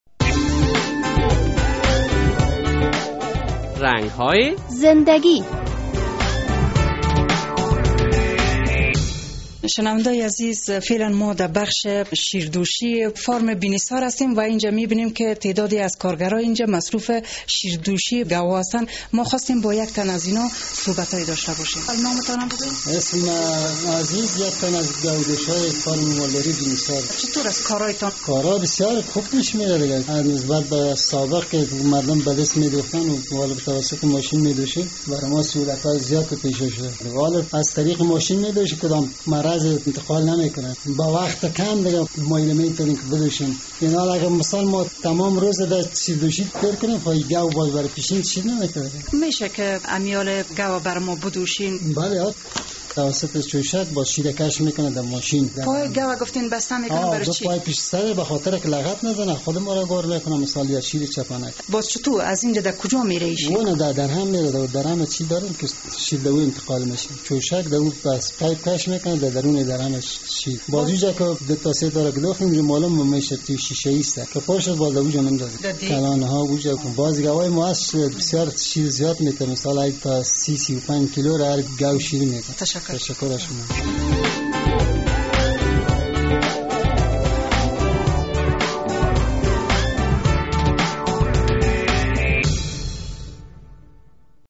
در این برنامهء رنگ های زندگی با او مصاحبه شده و وی در مورد کارش معلومات داده است...